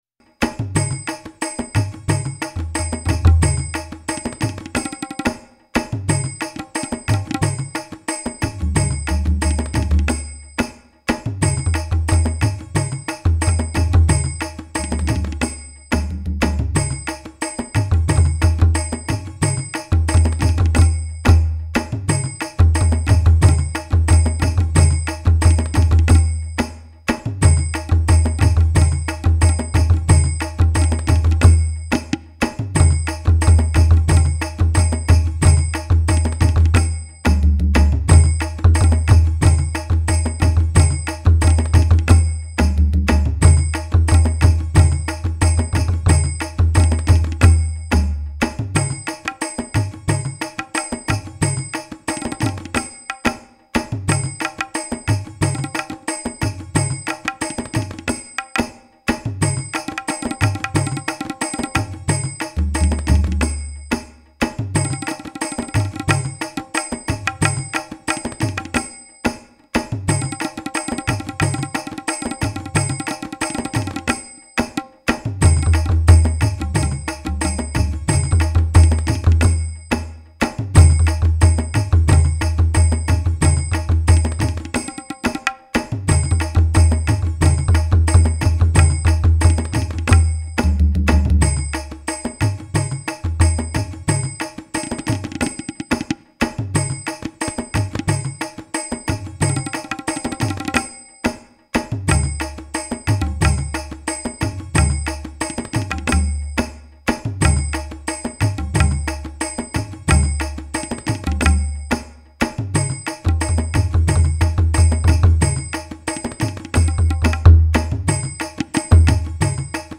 Master Percussionist
downtown New Haven Free Public Library.
TablaSongofCelebration.mp3